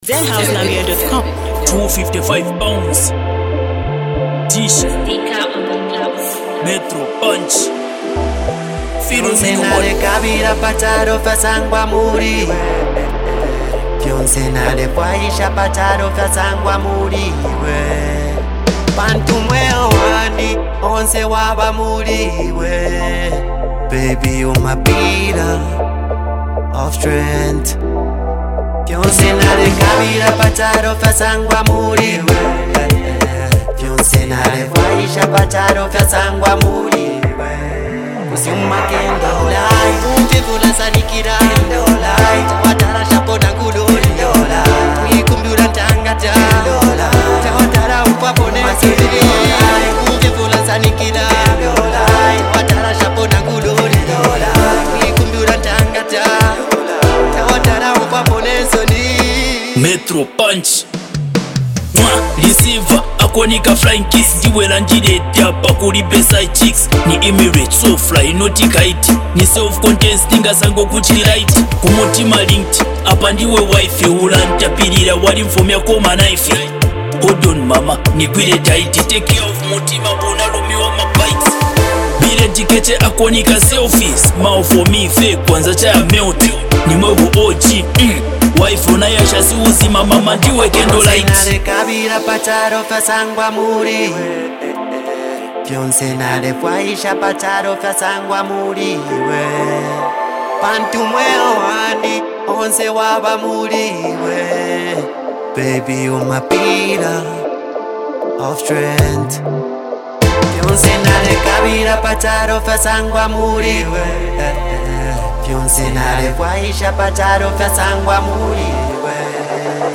love song
heartfelt melody
With smooth vocals and emotional lyrics